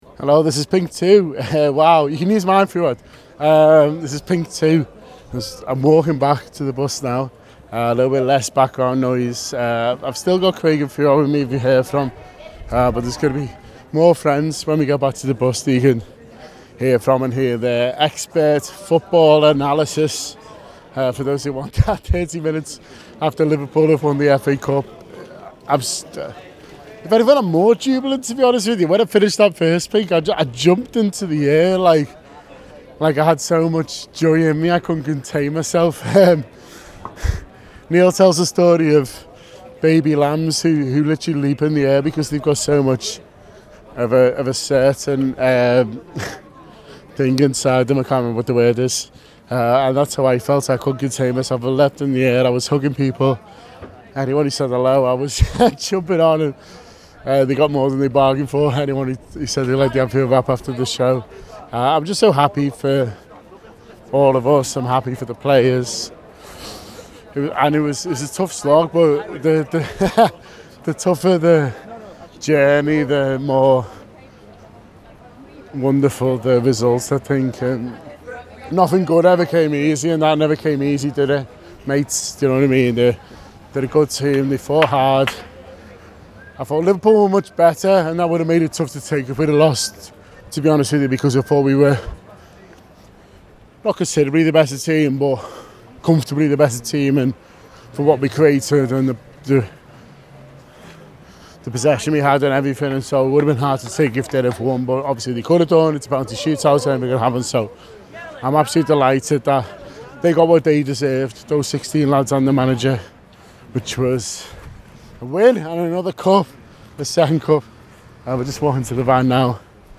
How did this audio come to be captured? Chelsea 0 Liverpool 0 (5-6 Pens): Post-Match Reaction Outside Wembley The Anfield Wrap’s post-match reaction podcast after Chelsea 0 Liverpool 0 (5-6 on penalties) in the FA Cup final outside Wembley.